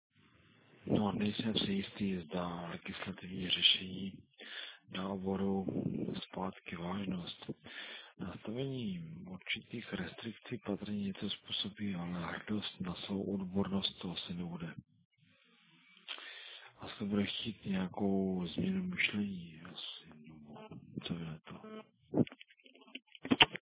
Bohužel audio je slabé a není celé srozumitelné, nešlo by to v trochu větší kvalitě a větší hlasitosti?